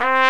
TRUMPET 2 C3.wav